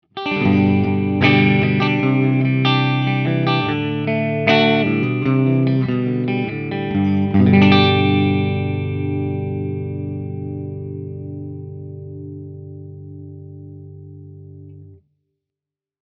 Tutte le clip audio sono state registrate con amplificatore Fender Deluxe e una cassa 2×12 con altoparlanti Celestion Creamback 75.
Clip 5 – Les Paul Clean, Over Drive in Original Buffered Bypass
Chitarra: Gibson Les Paul (entrambi i pickup)
LP-Clean.mp3